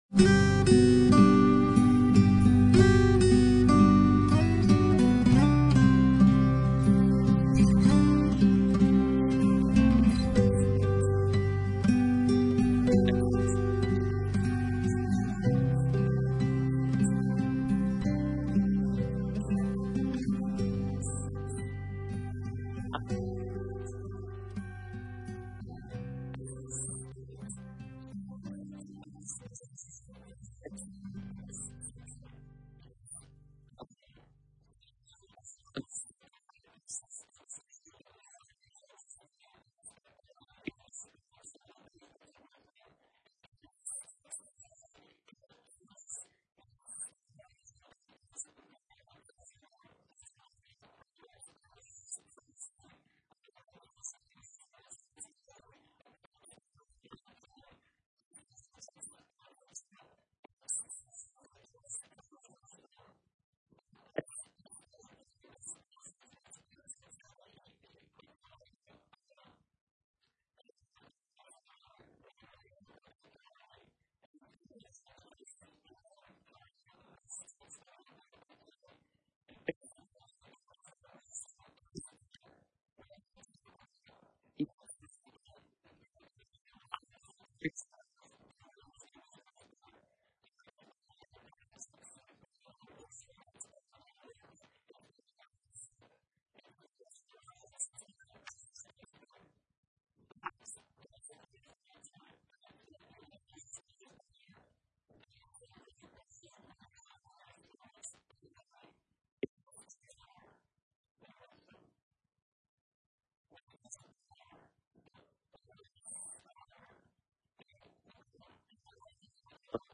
Ecclesiastes 9:13-11:6 Service Type: Sunday Bible Study « By Faith